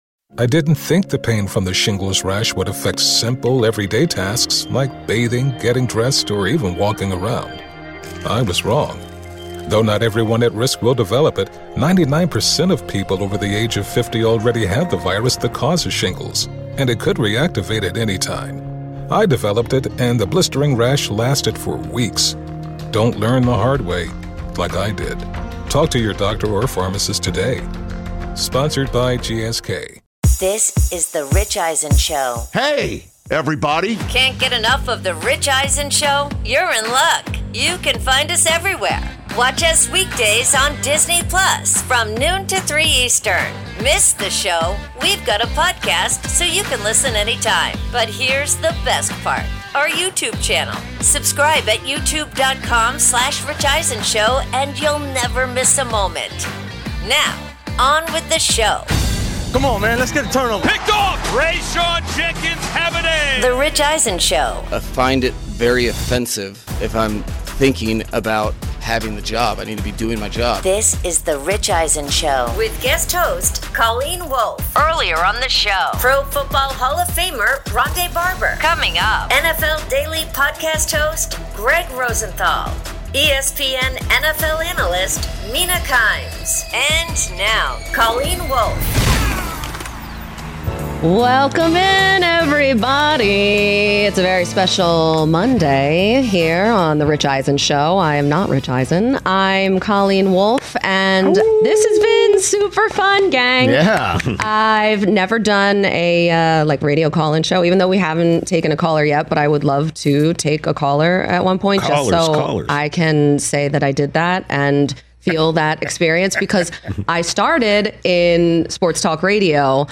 Guest host Colleen Wolfe and the guys debate how much patience the 0-7 Jets should have with rookie head coach Aaron Glenn.